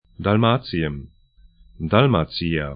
Aussprache
Dalmatien dal'ma:tsĭən Dalmacija